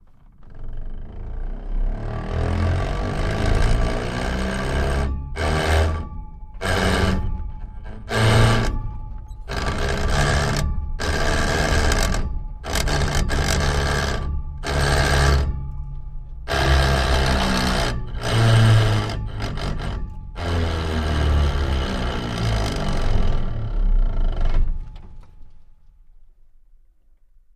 Car Transmission Manual; Starts Rolling And Goes Steady With Very Bad Gear Grinds, Winds Down To Stop At End, No Motor, Interior Perspective 4x